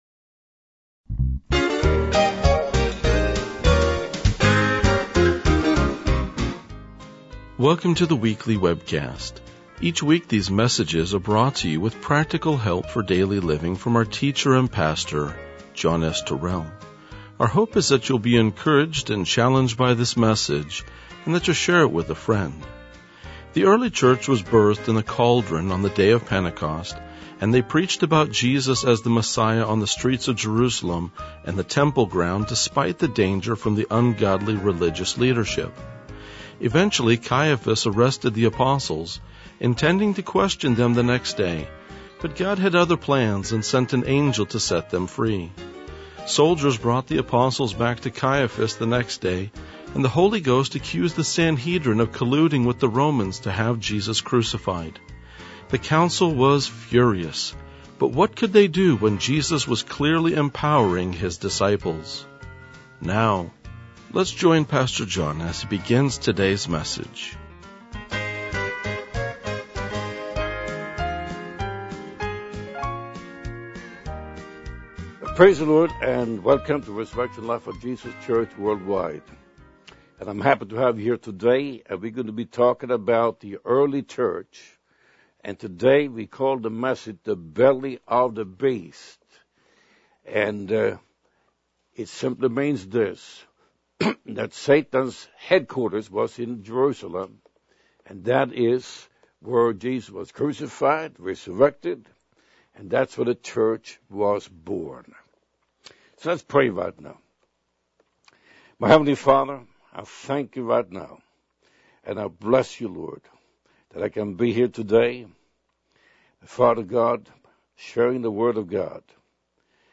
RLJ-2003-Sermon.mp3